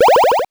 8 bits Elements
powerup_15.wav